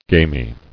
[gam·y]